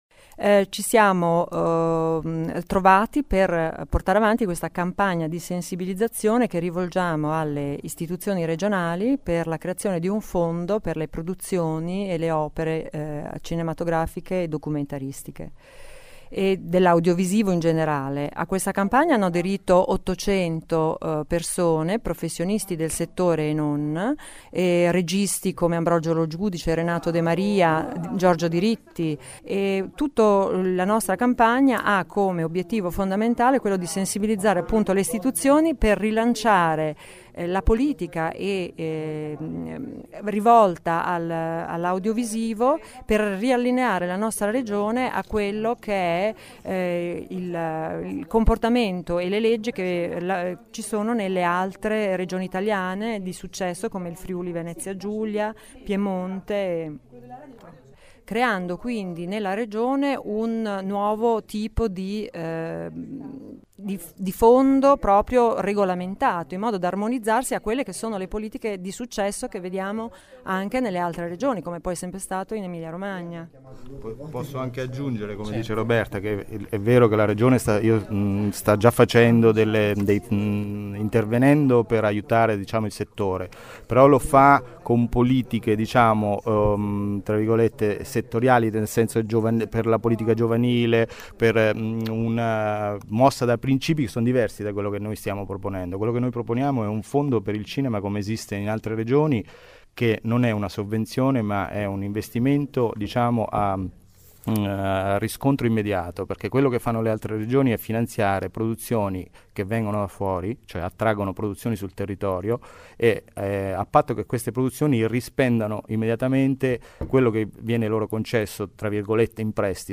due membri di ProFilm.